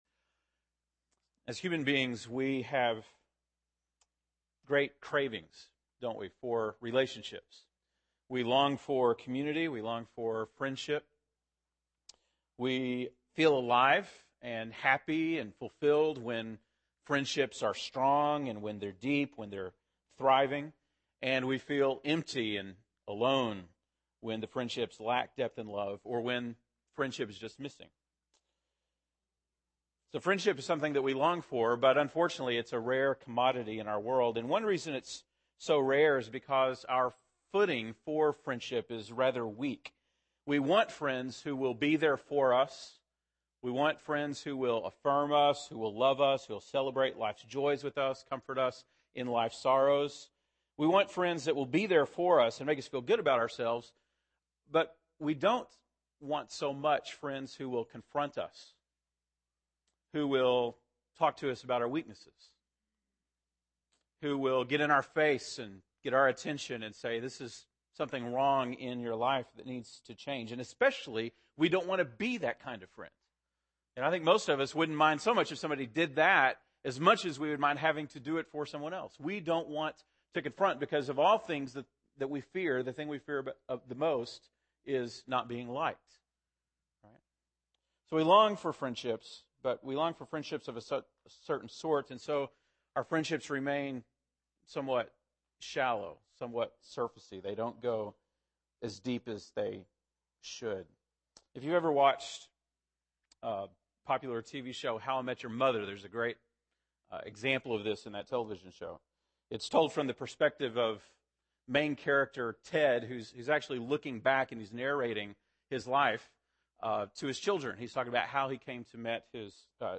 February 13, 2011 (Sunday Morning)